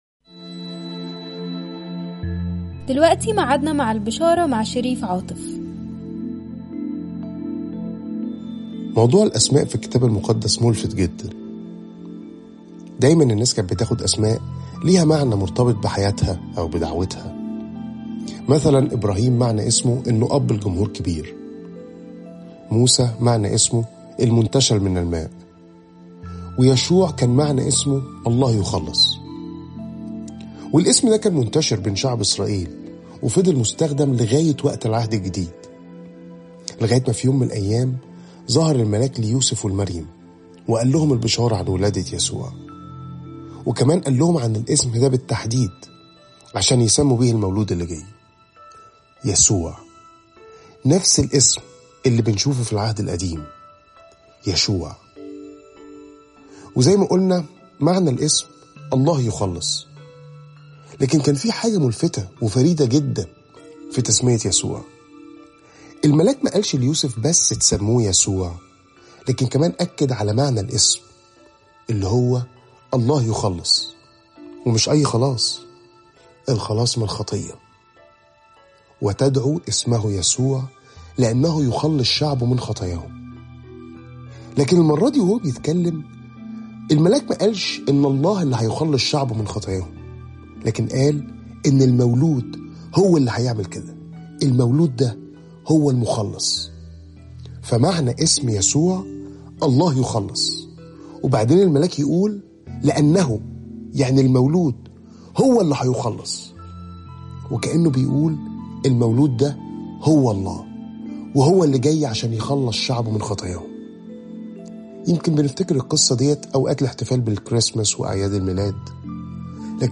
تأملات مُركّزة باللهجة المصرية عن عُمق وروعة صليب المسيح وقيامته